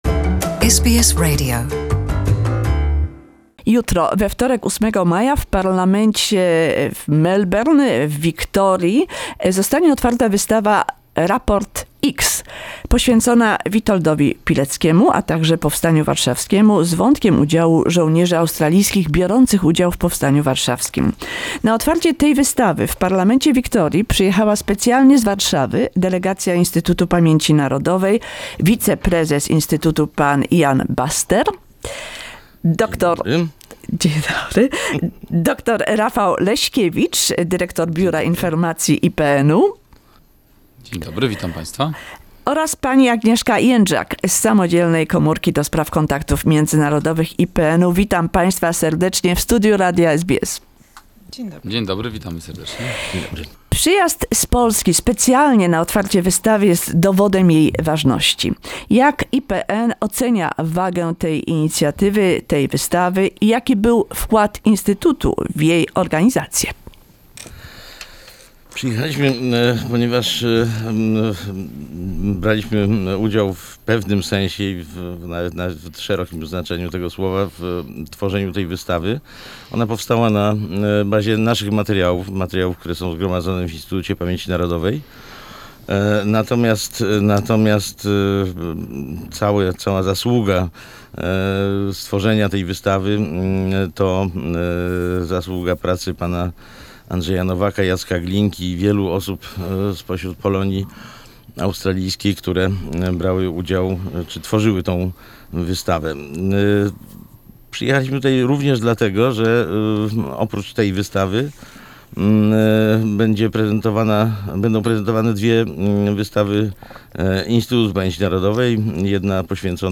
A conversation with the guests from National Institute of Remembrance who came to Melbourne for the opening of the Exhibition on Captain Witold Pilecki in the Victorian’s Parliament.